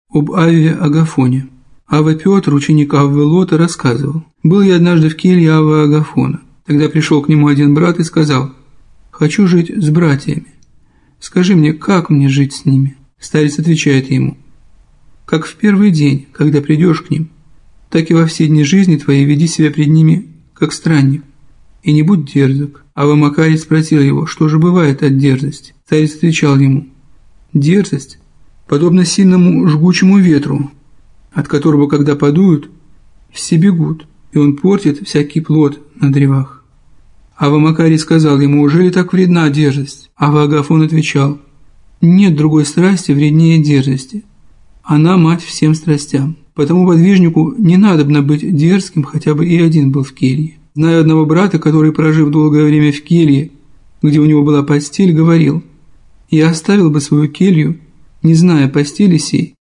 Аудиокнига Достопамятные сказания о подвижничестве святых и блаженных отцов | Библиотека аудиокниг